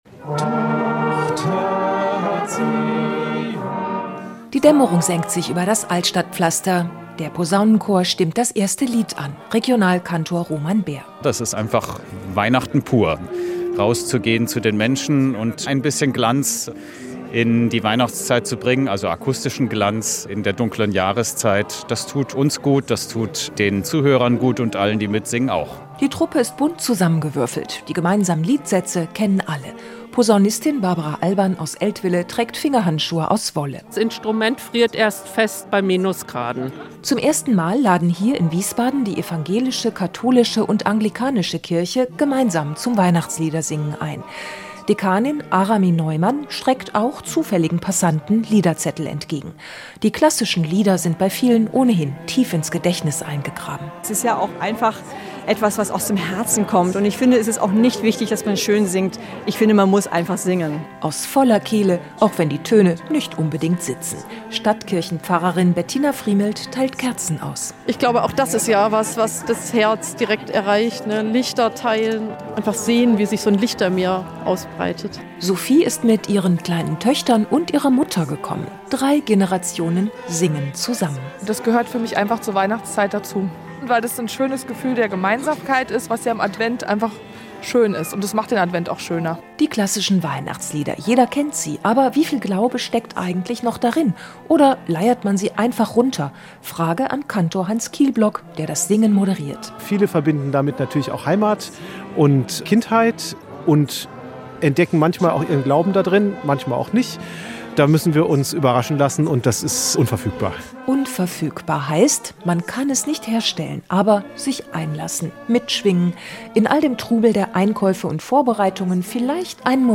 In Wiesbaden luden die evangelische, die katholische und die anglikanische Kirche zum gemeinsamen Weihnachtsliedersingen ein. Das gemeinsame Singen kommt bei den Passantinnen und Passanten gut an.